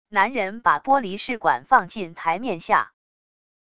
男人把玻璃试管放进台面下wav音频